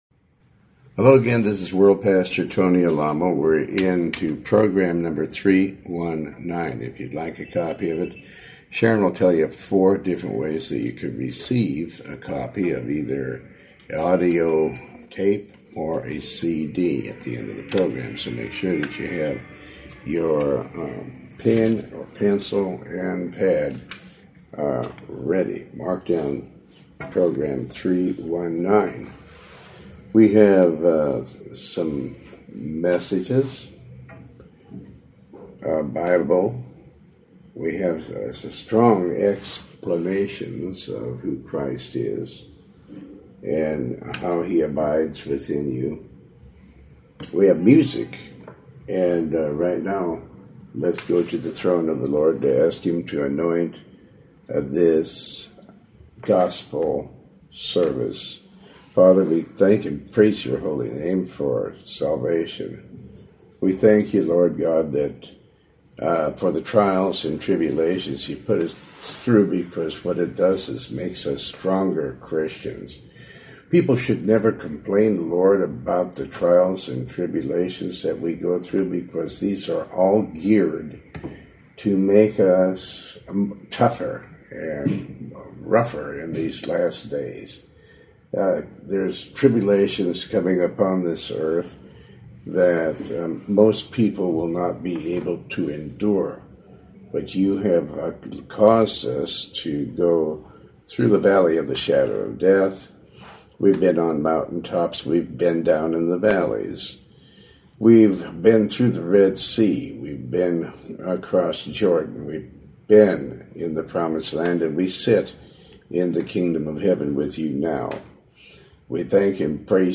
Talk Show Episode, Audio Podcast, Tony Alamo and Program 319 on , show guests , about pastor tony alamo,Tony Alamo Christian Ministries,Faith, categorized as Health & Lifestyle,History,Love & Relationships,Philosophy,Psychology,Christianity,Inspirational,Motivational,Society and Culture